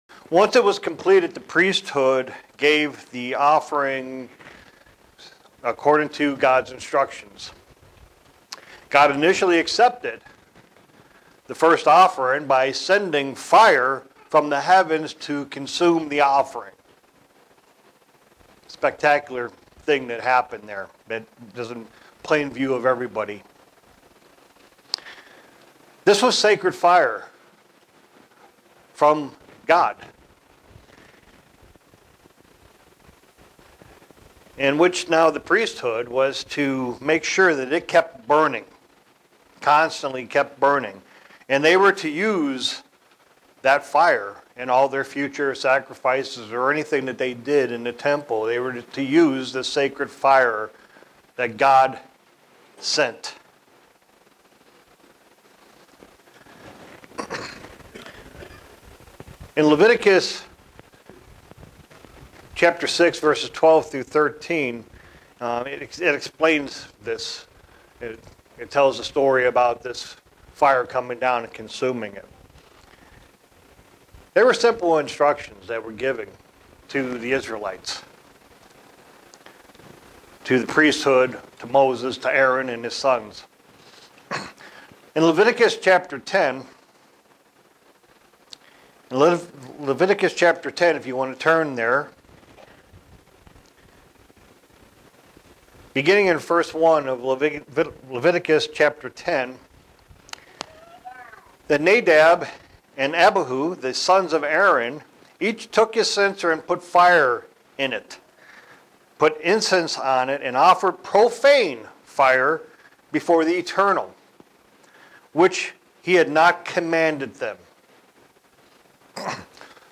Sermons
Given in Buffalo, NY